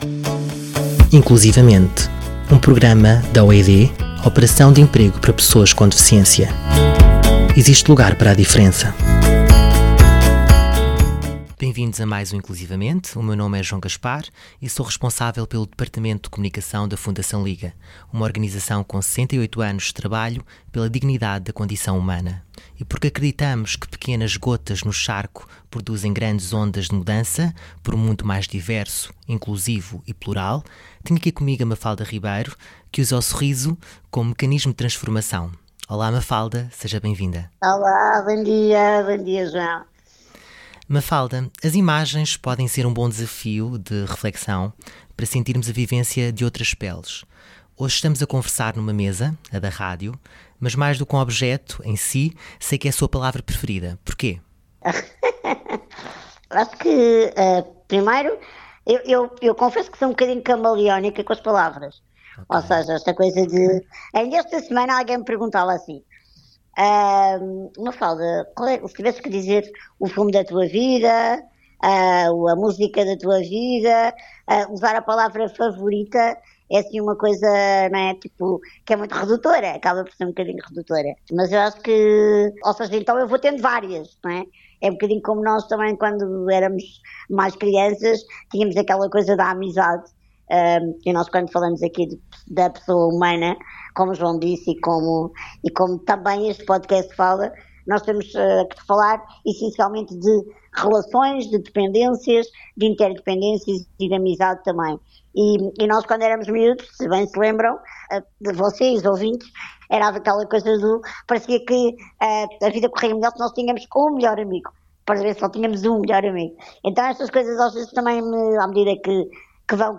Existe lugar para a diferença. Um programa da OED – Operação de Emprego para Pessoas com Deficiência, de conversas sobre a inclusão.